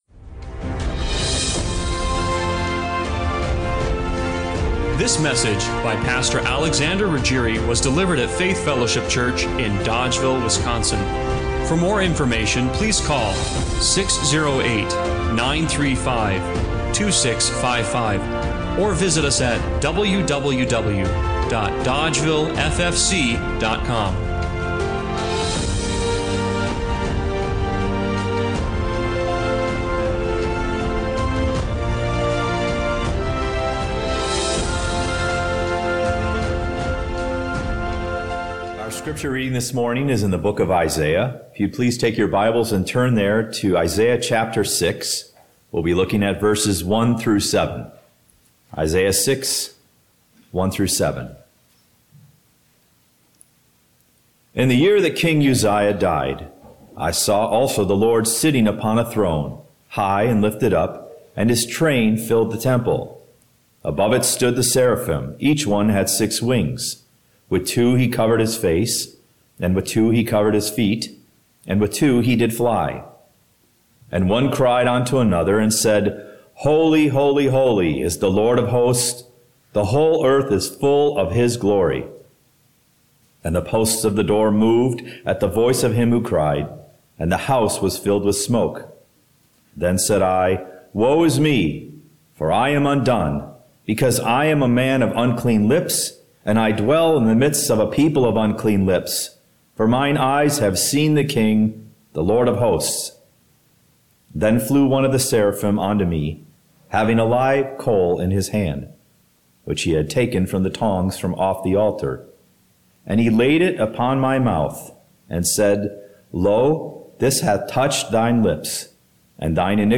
Isaiah 6:1-7 Service Type: Sunday Morning Worship What if holiness isn’t something you achieve